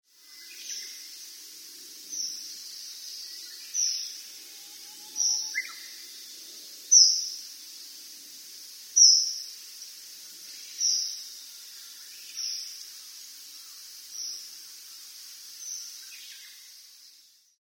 kawasemi_c1.mp3